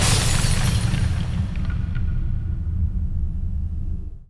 Explosion.WAV